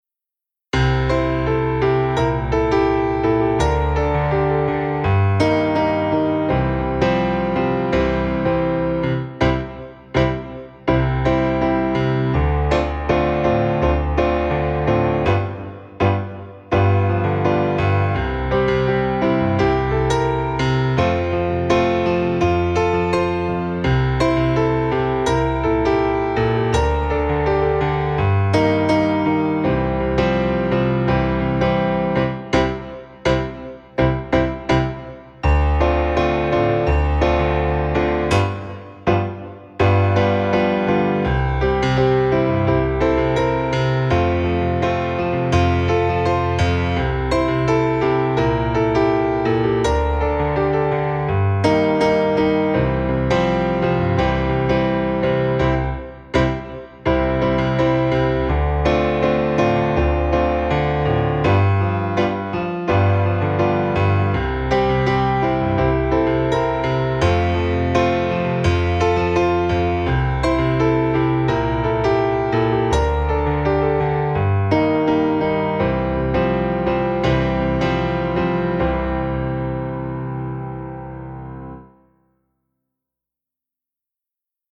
Raz dva tri (s playbackom)
pieseň zo školenia (2018 Račkova dolina) – noty s akordami, prezentácia a playback